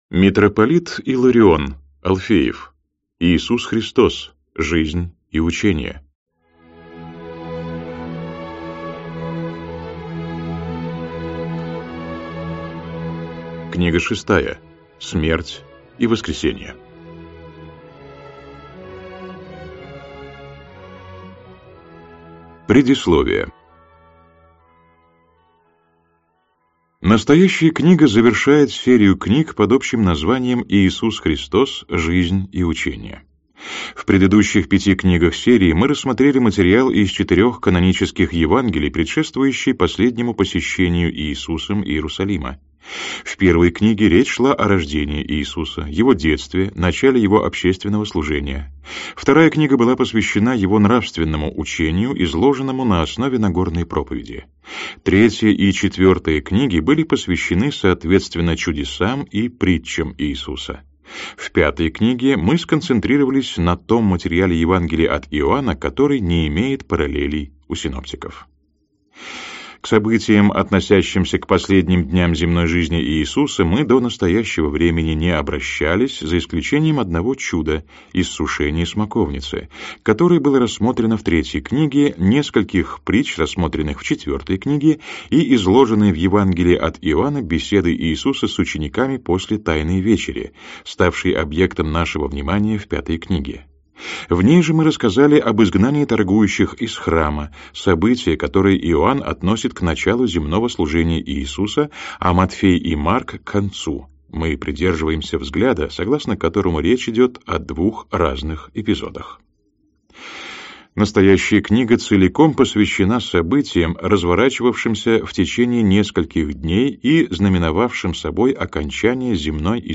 Аудиокнига Иисус Христос. Жизнь и учение. Книга VI. Смерть и Воскресение.